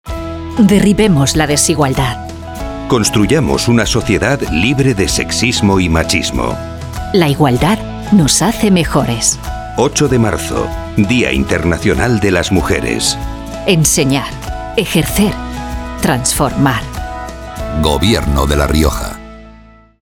Cuñas radiofónicas